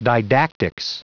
Prononciation du mot didactics en anglais (fichier audio)
Prononciation du mot : didactics